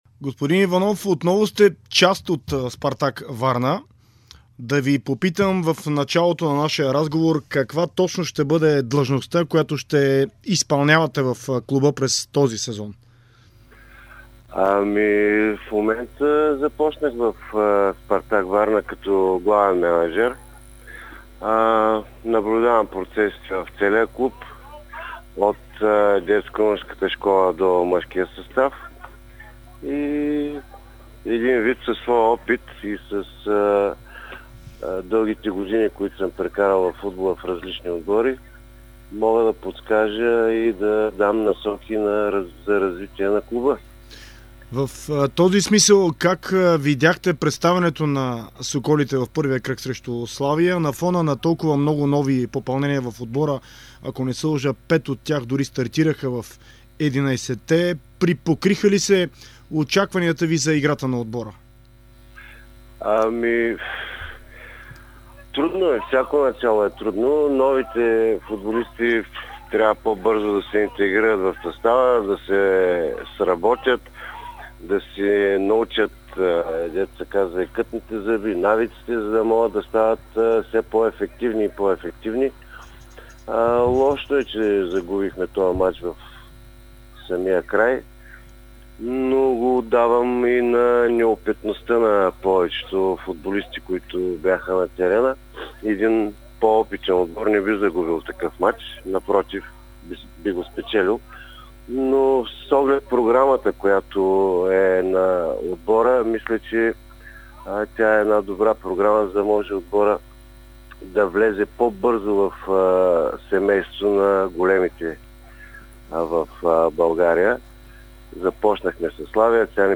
Георги Иванов - Геша, който изпълнява длъжността главен мениджър в Спартак Варна, сподели в интервю за Дарик Радио и dsport, че е впечатлен от организацията в клуба.